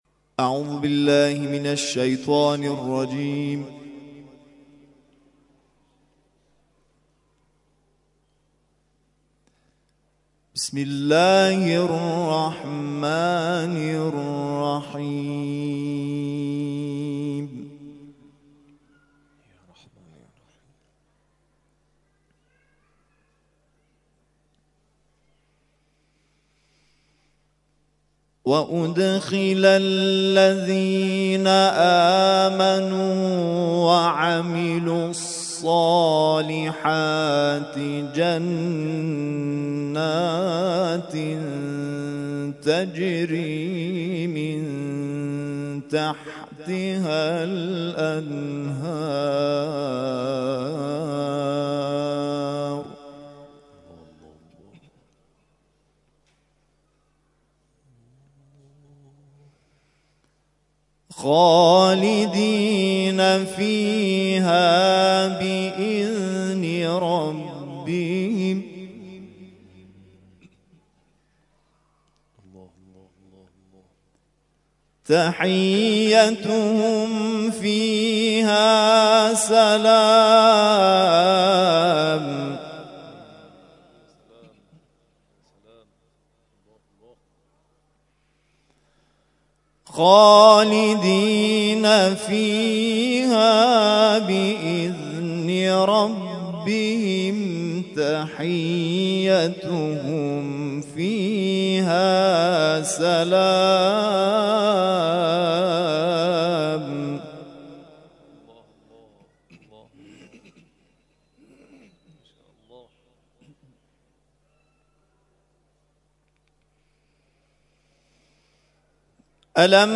گروه جلسات و محافل: محفل انس با قرآن کریم این هفته آستان عبدالعظیم الحسنی(ع) با تلاوت قاریان ممتاز و بین‌المللی کشورمان برگزار شد.